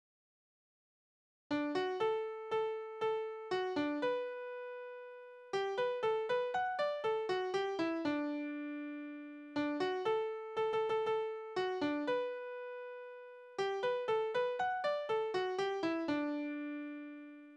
Tonart: D-Dur
Taktart: 2/2
Tonumfang: große Dezime
Besetzung: vokal